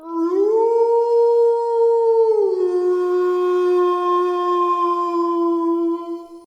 wolf-howl-2.ogg